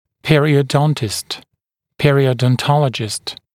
[ˌperɪəu’dɔntɪst] [ˌperɪəuˌdɔn’tɔləʤɪst][ˌпэриоу’донтист] [ˌпэриоуˌдон’толэджист]пародонтолог